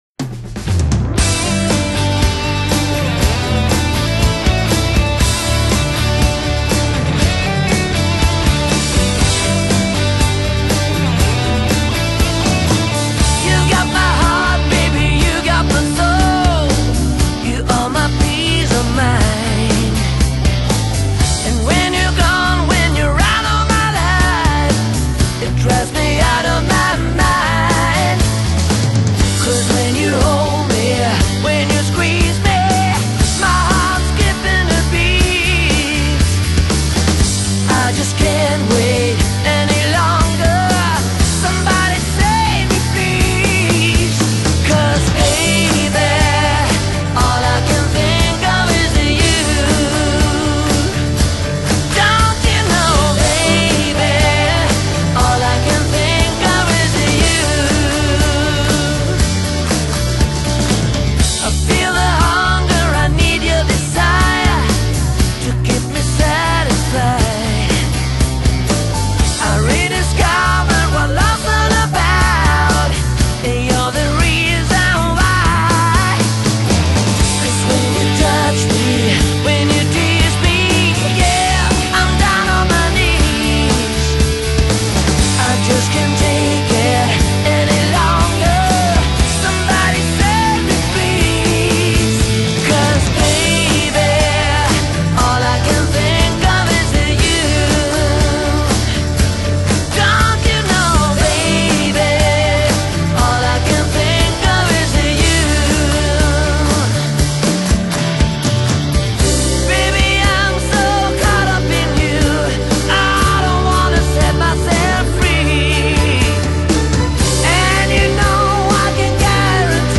Genre: Melodic Hard Rock